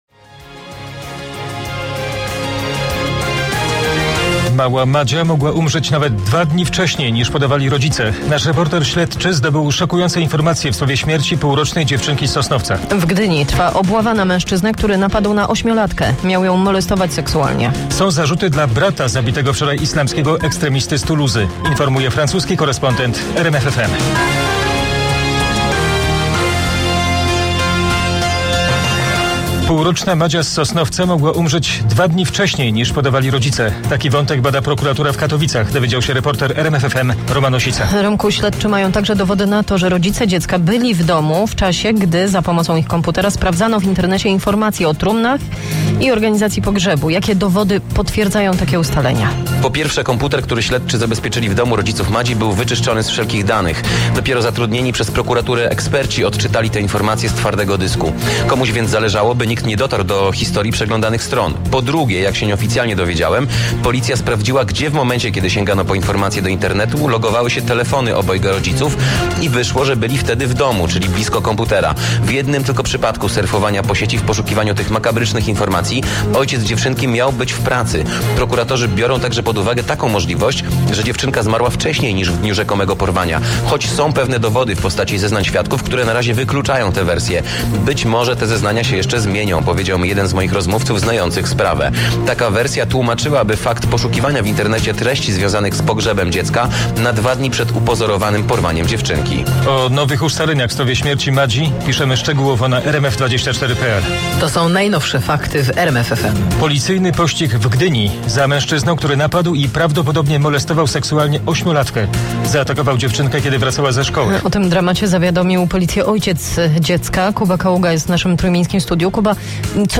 2012-03-23 W serwisie informacyjnym RMF FM ukazał się